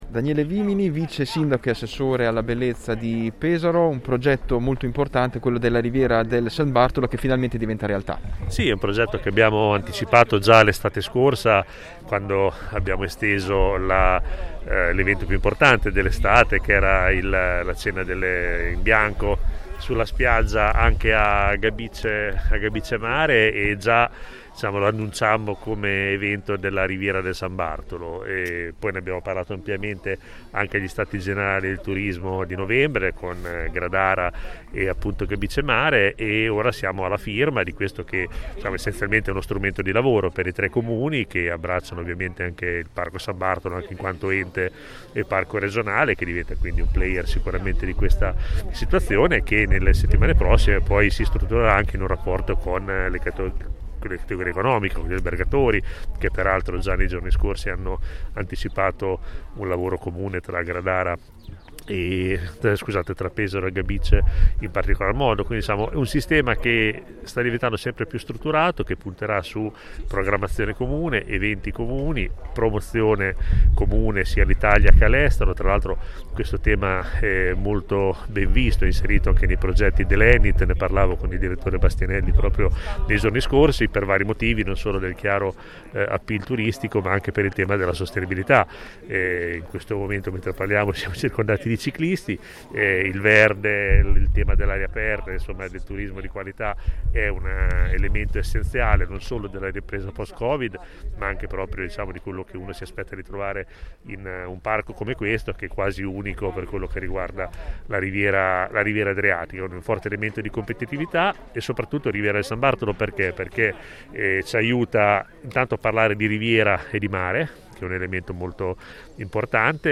Siglato il protocollo d’intesa tra i comuni di Pesaro, Gradara e Gabicce Mare, la Riviera del San Bartolo, si prefigge lo scopo di offrire servizi di una qualità anche superiore della più conosciuta riviera romagnola. Ai nostri microfoni